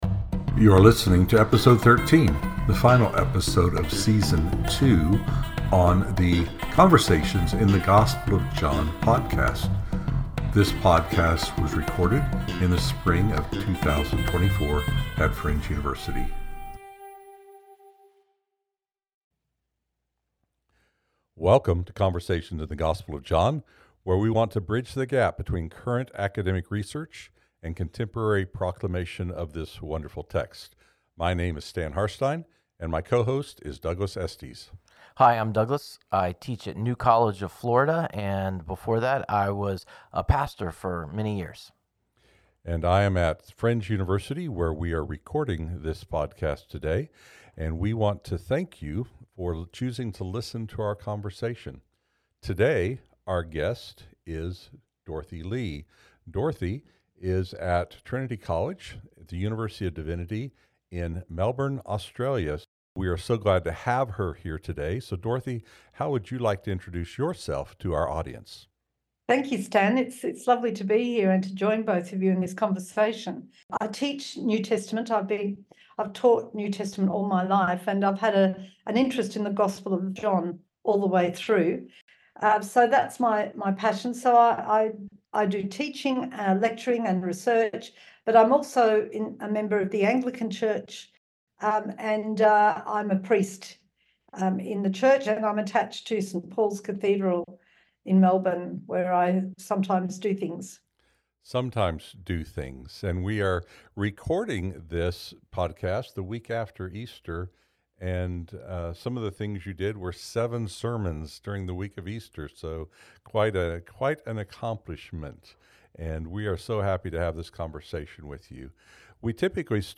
invite other Johannine scholars to discuss how their research can impact preaching and teaching.